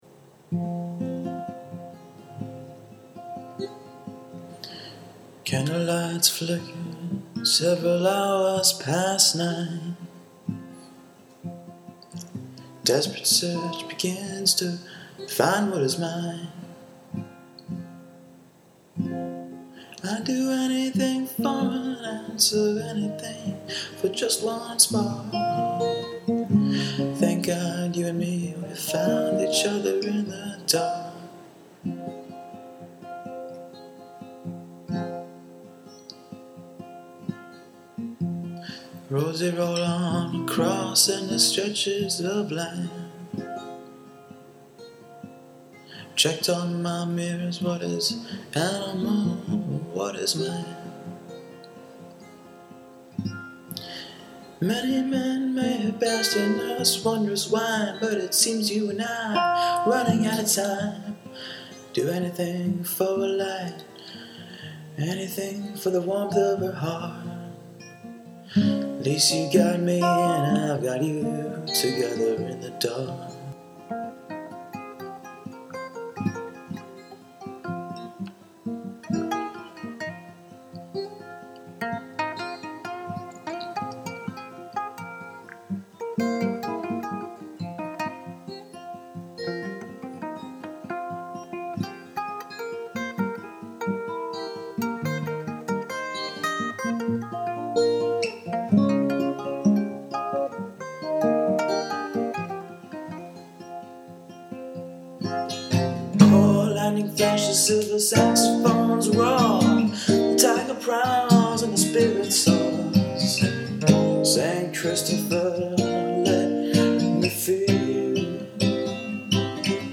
Demo Recording: